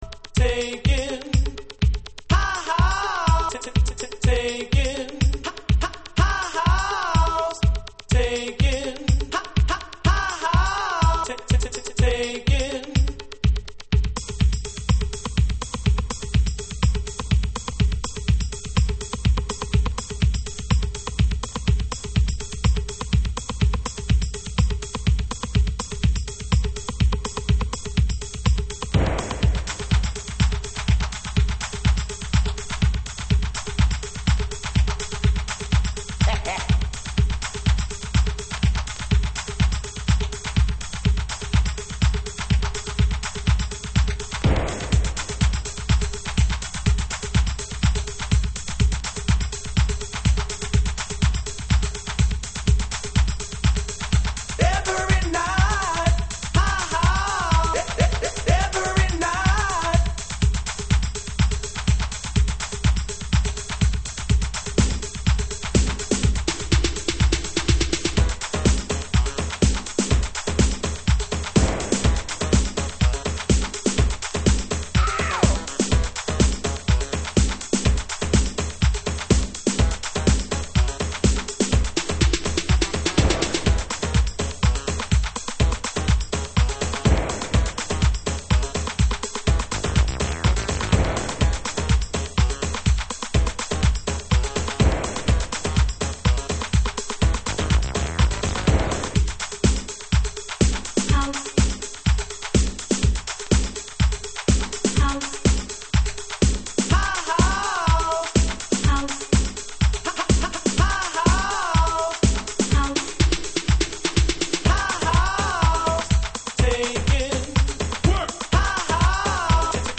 ストレートなサンプリングとTRの音を弄れるブレイクビーツハウス。